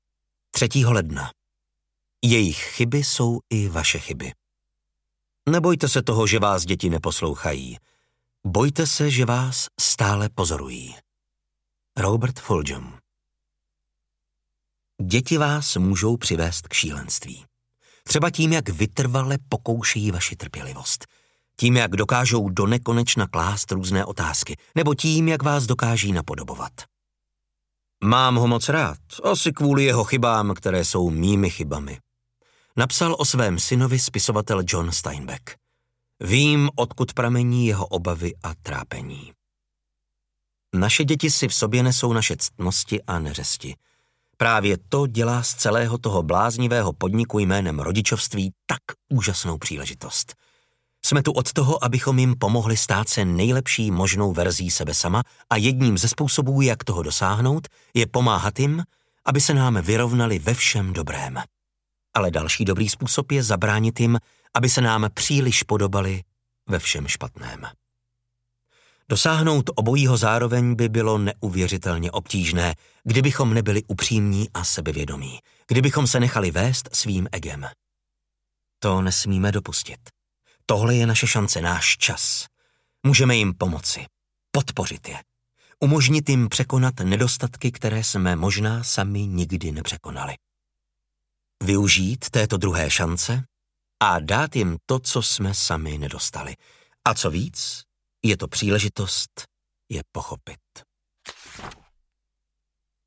Tátou každý den audiokniha
Ukázka z knihy